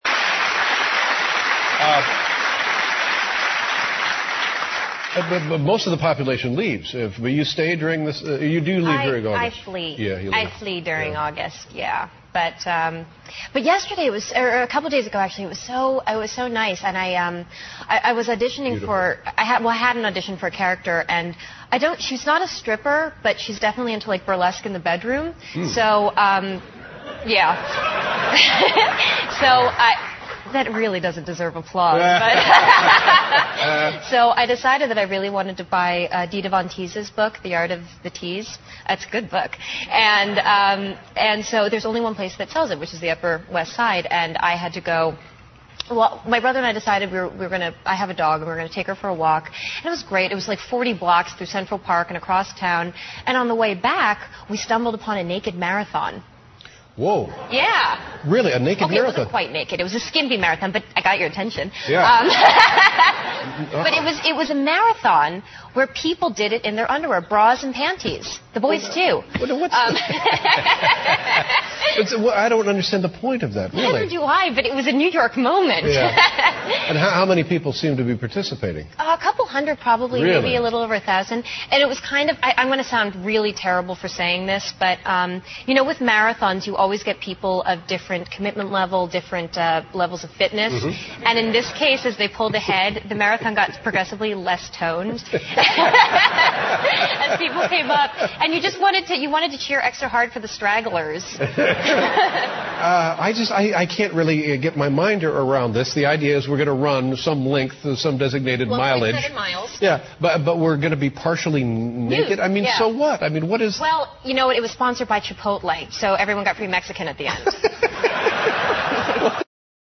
在线英语听力室访谈录 Interview 2007-08-20&08-21,